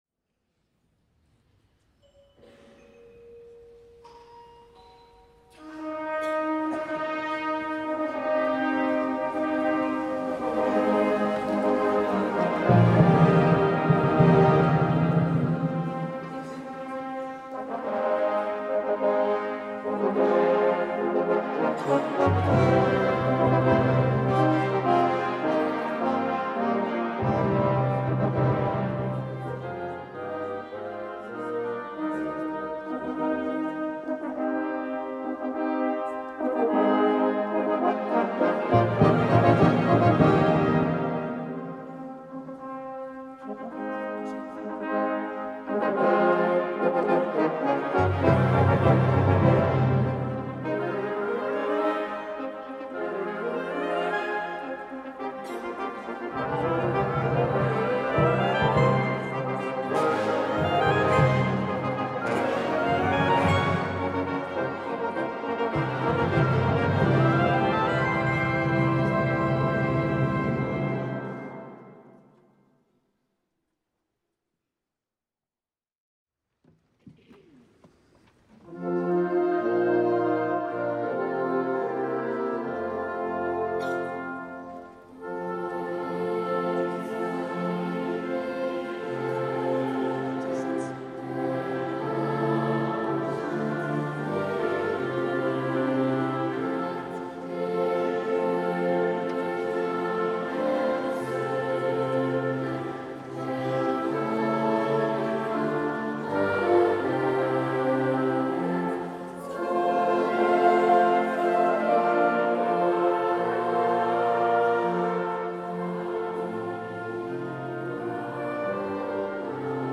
„Deutsche Messe“ von Franz Schubert – die Audiodatei des Schulabschlussgottesdienstes!